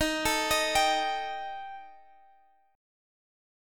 Listen to D#Mb5 strummed